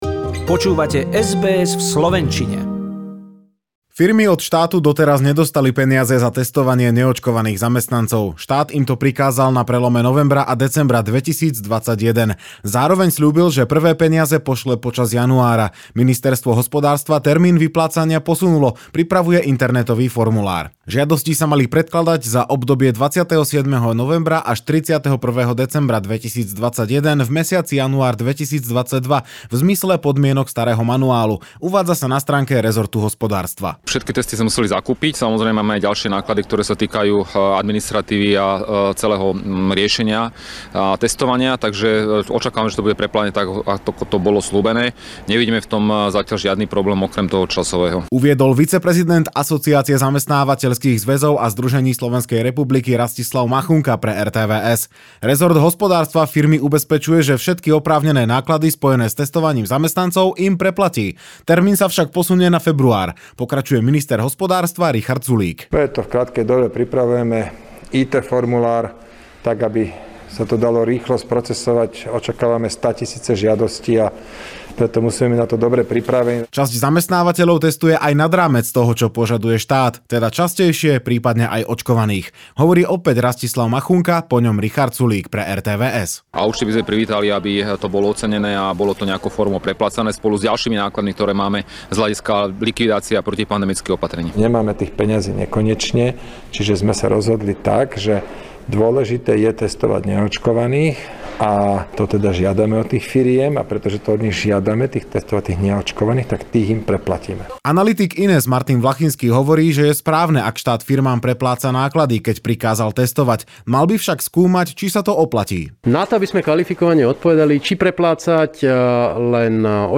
Reportáž